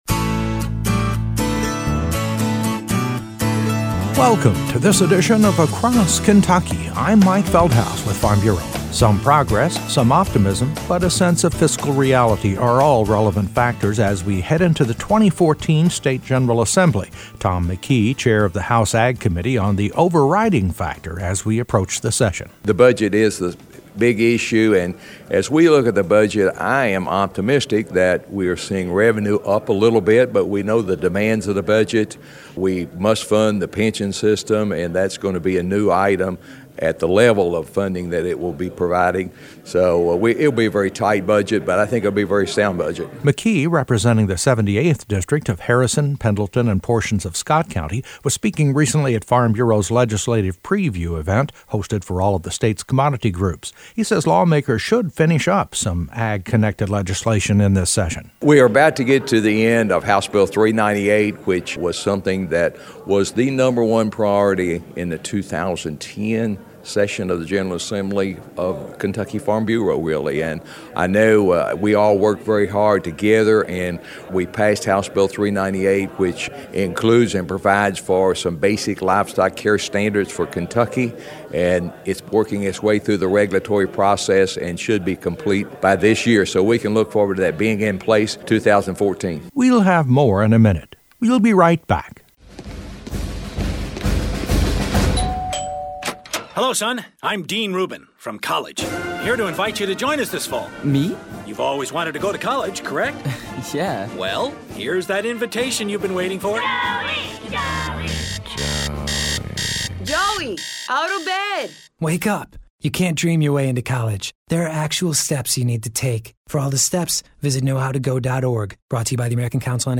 talks with Kentucky House Ag Committee Chair, Tom McKee of Harrison County, getting a preview of the 2014 legislative session and what it might hold for agriculture. McKee says the budget will be the main focus of action, but there will be some final touches for the state’s animal care standards for 2014, and he discusses a tax change aimed at encouraging farmer-connected efforts in assisting a statewide food bank program.